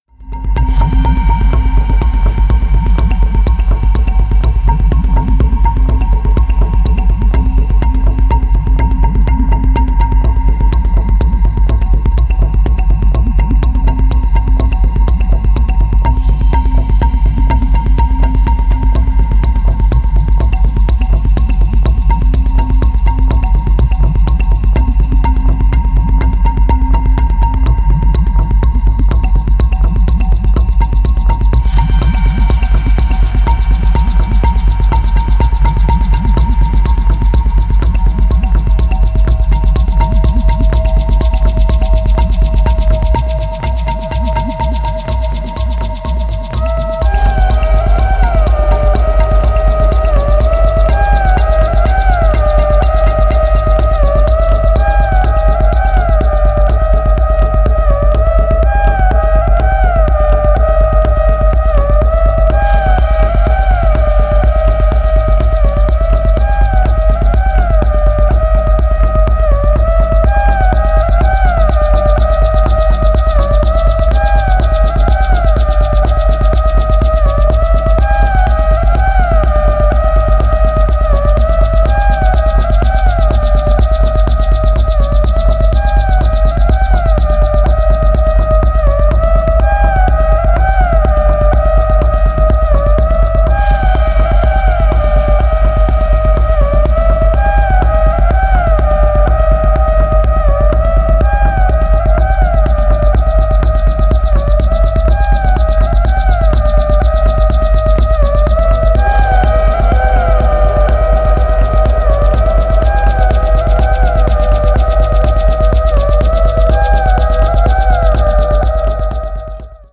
幻惑的なフルートがインパクト絶大な